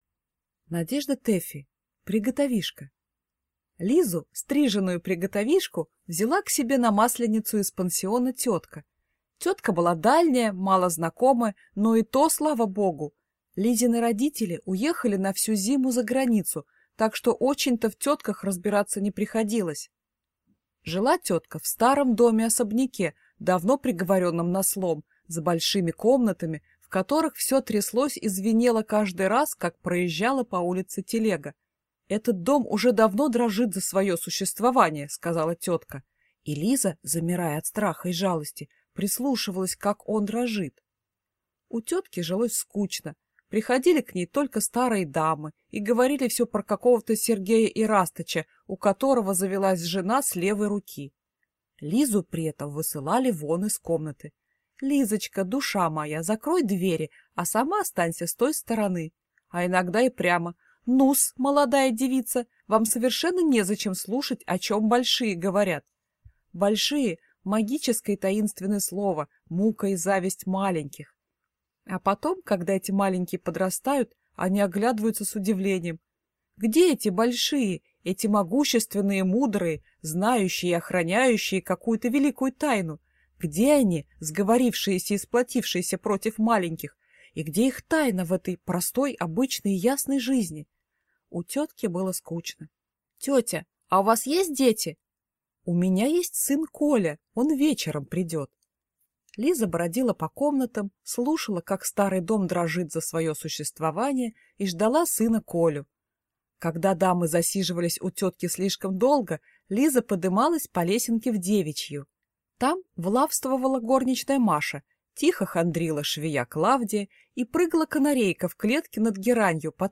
Аудиокнига Приготовишка | Библиотека аудиокниг